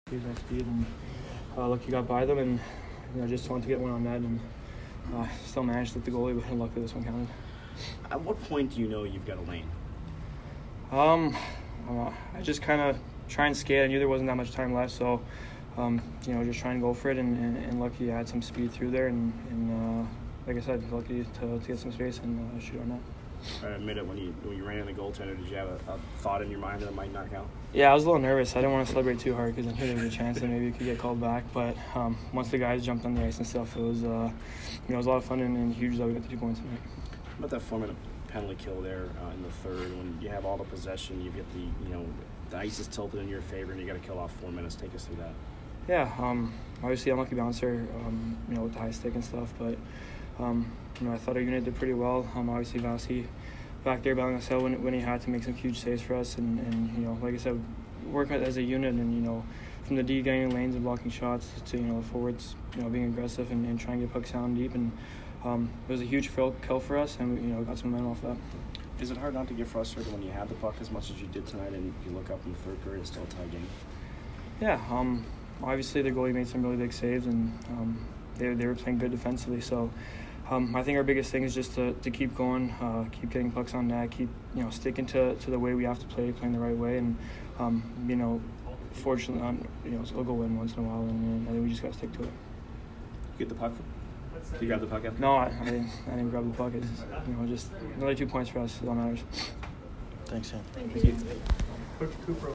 Cirelli post-game 12/17